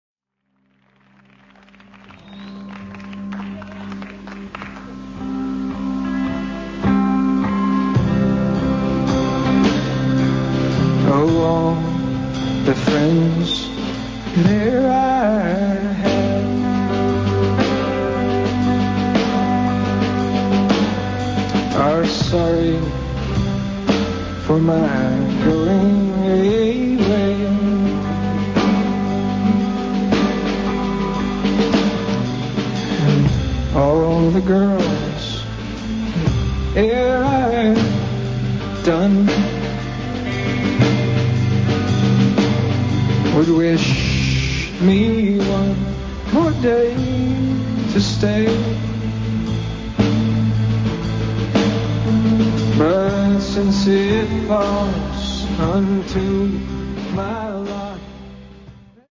Sessions radios & lives inédits